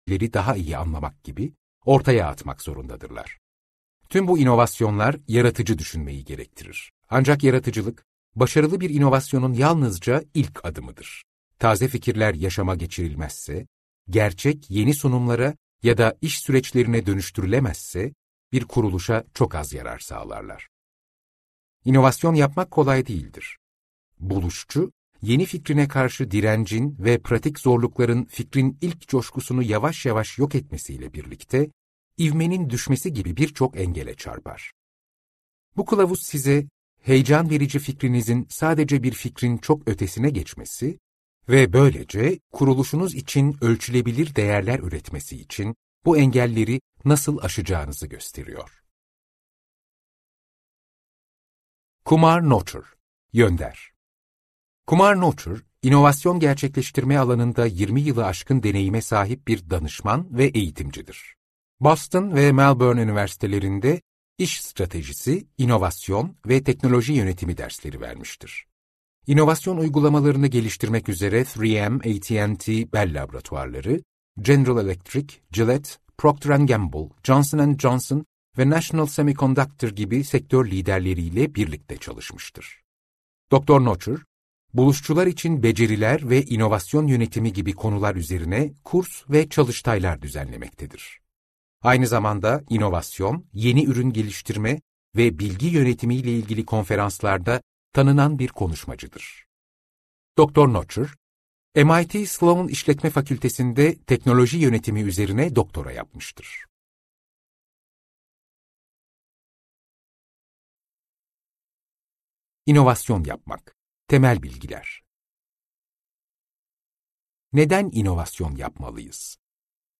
İnovasyon Yapmak - Seslenen Kitap